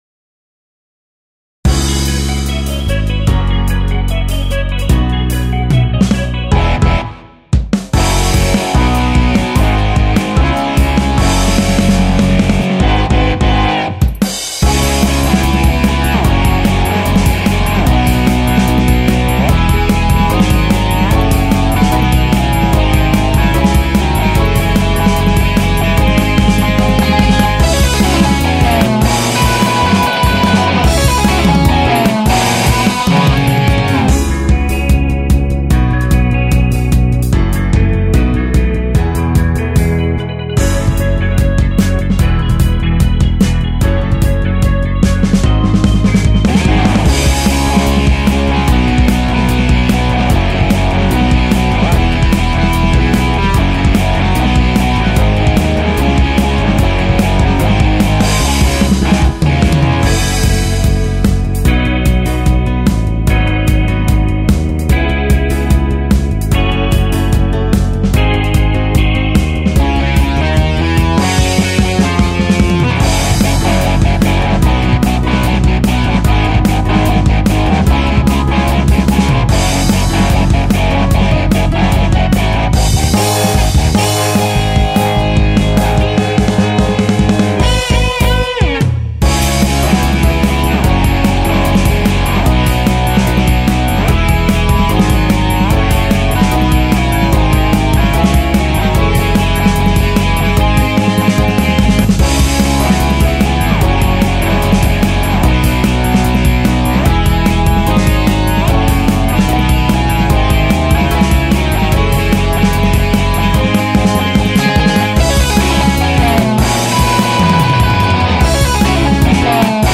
Drum/Bass/Piano/Organ/Lead